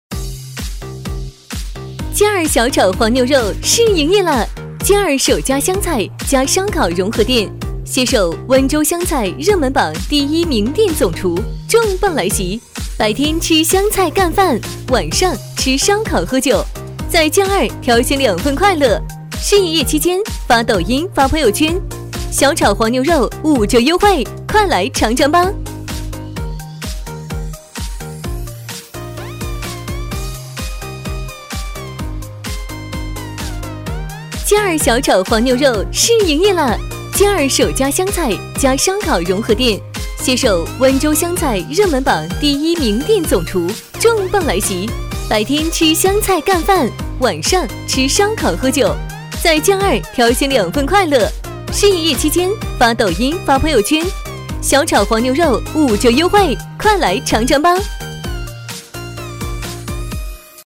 女3号